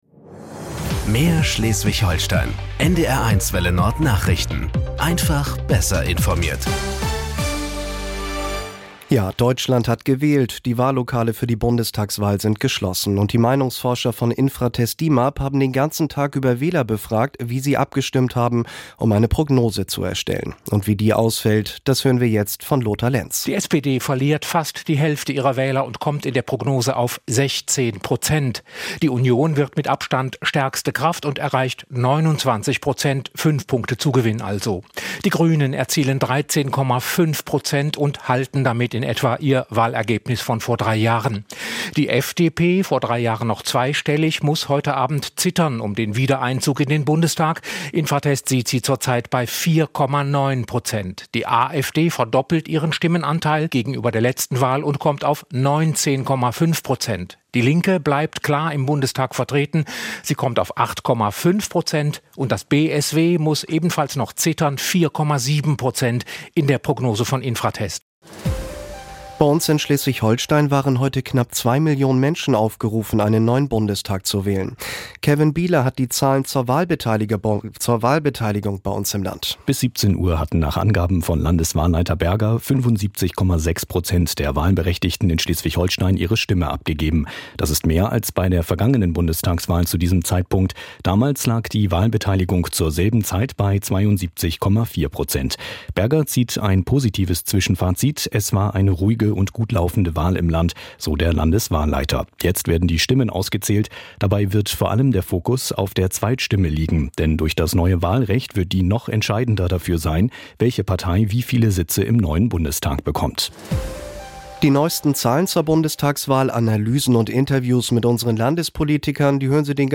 Nachrichten 16:00 Uhr.